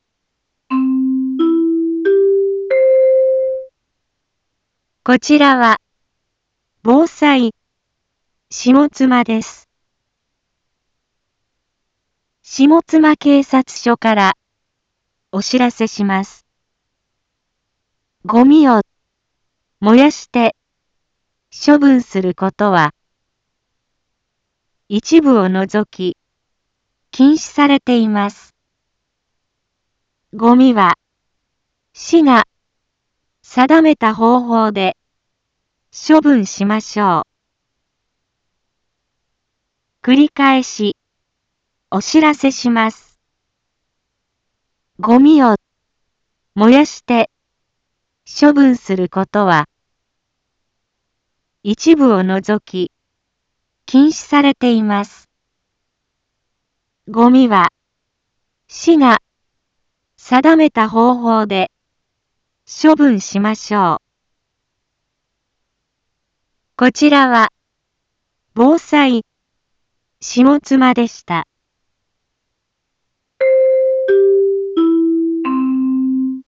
Back Home 一般放送情報 音声放送 再生 一般放送情報 登録日時：2024-10-25 10:01:21 タイトル：ごみの野焼き禁止 インフォメーション：こちらは、ぼうさいしもつまです。